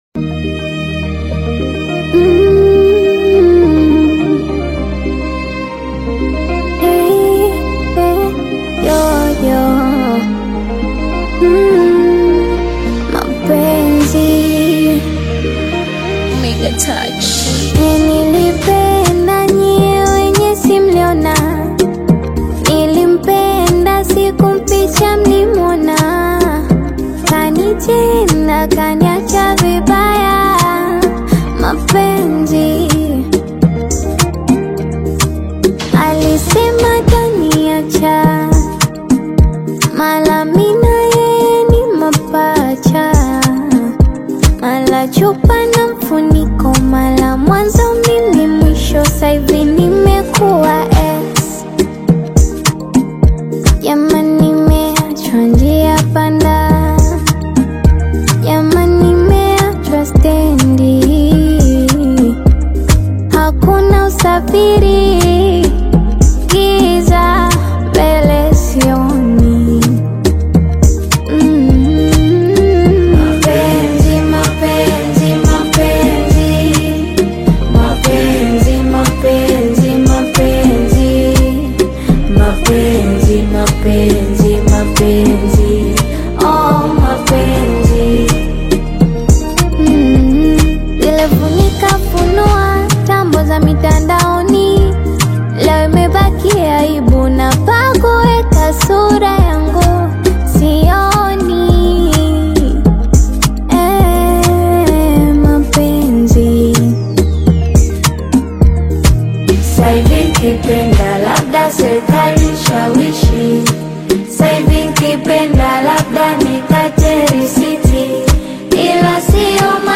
infectious beat